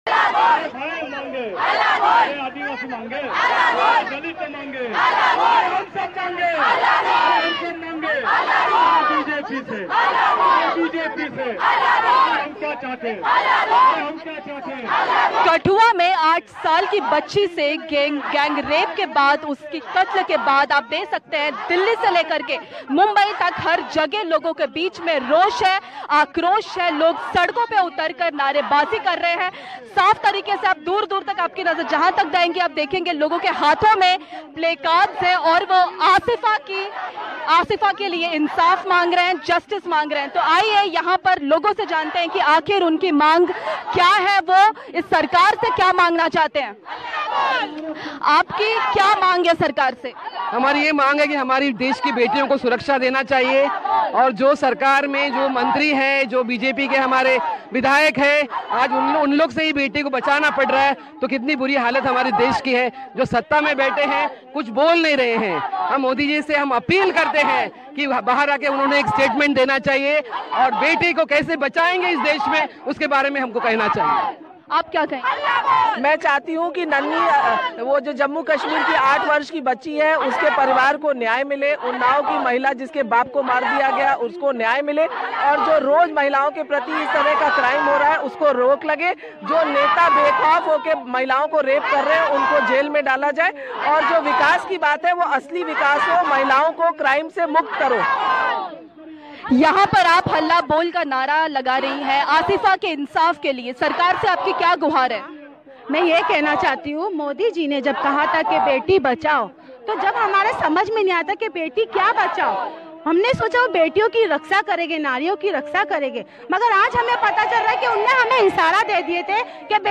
News Report